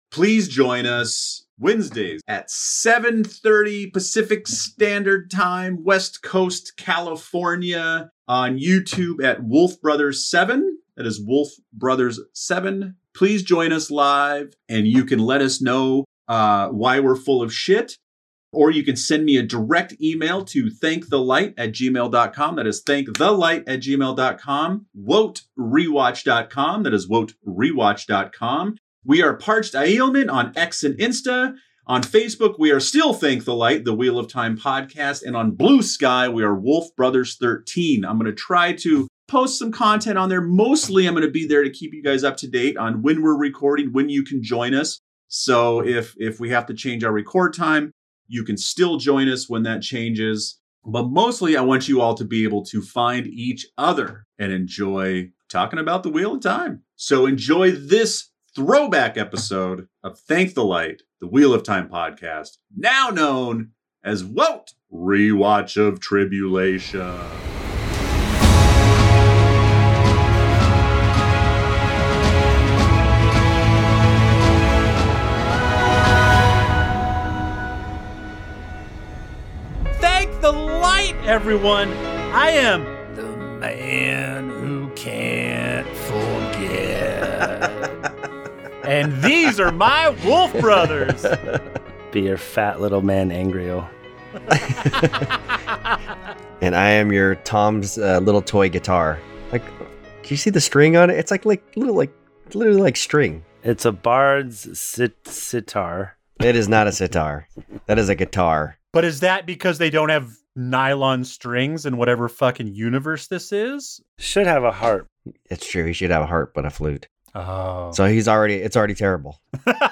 Join us for a spirited conversation that promises to entertain and provoke thought about this epic saga!